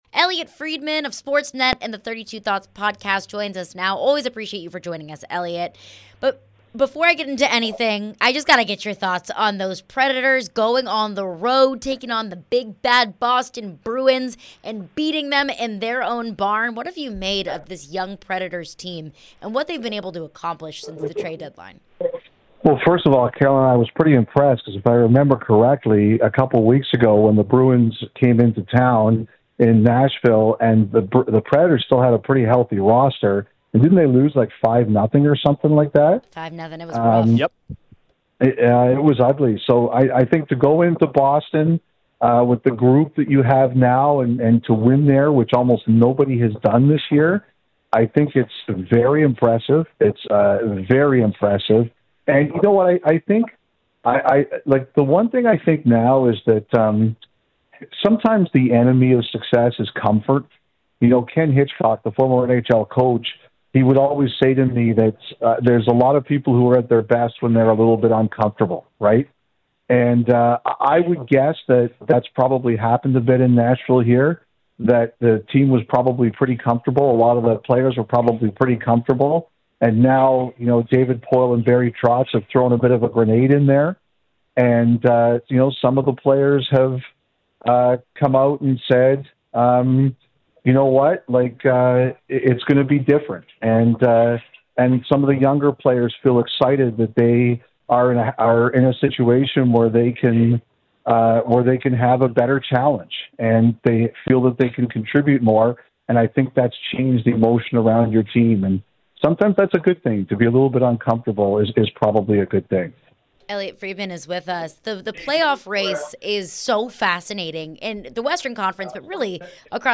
Elliotte Friedman Interview (3-30-23)